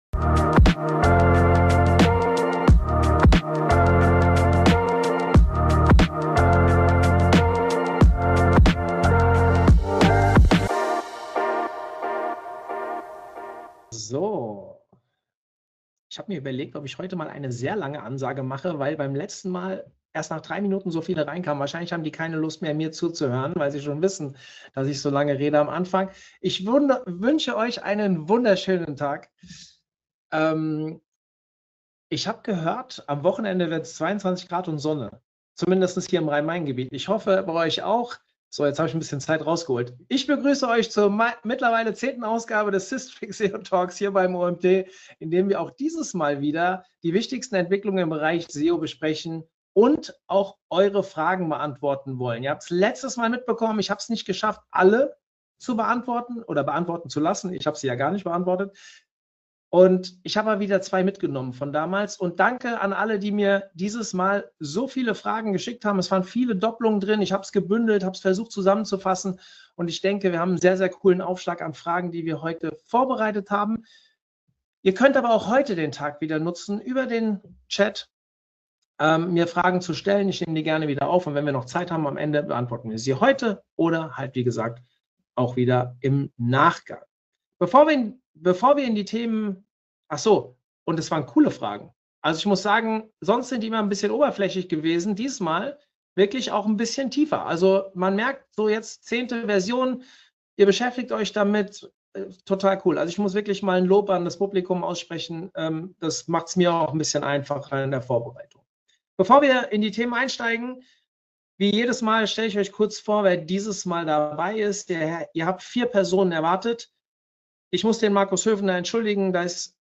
Diskussionsrunde